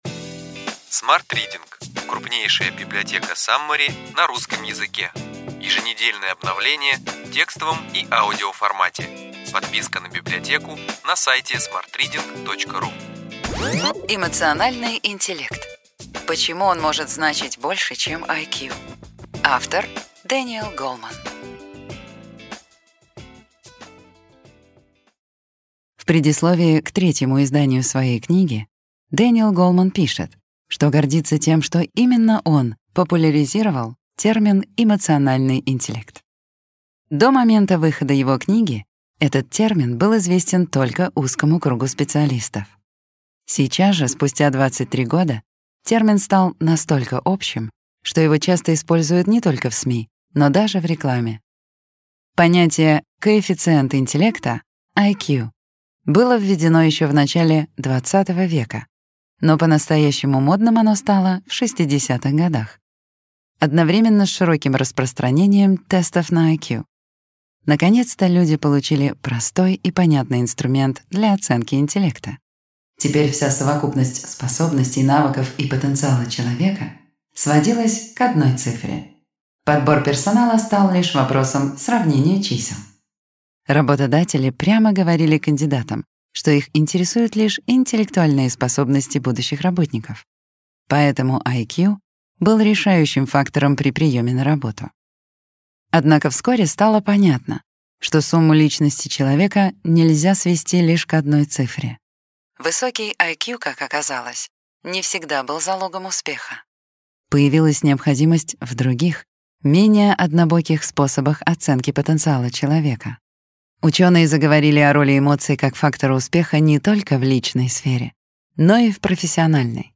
Аудиокнига Ключевые идеи книги: Эмоциональный интеллект. Почему он может значить больше, чем IQ. Дэниел Гоулман | Библиотека аудиокниг